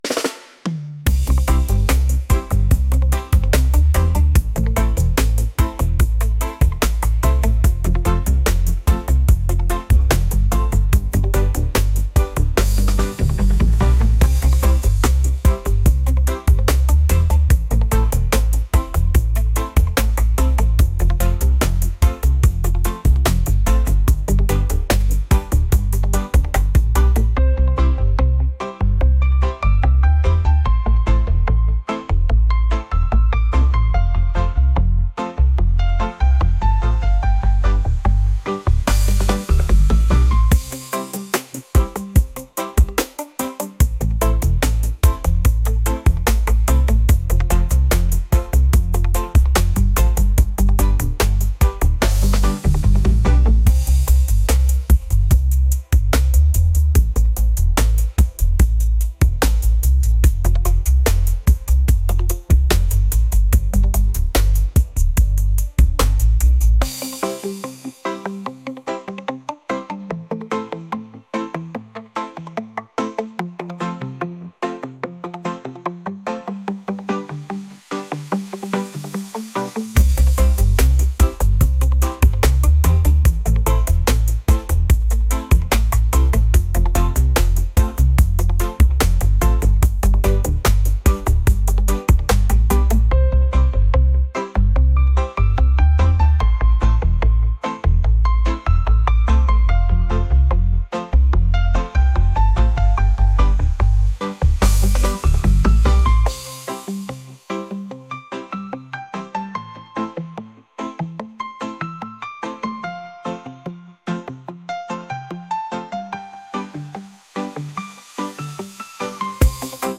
reggae | lofi & chill beats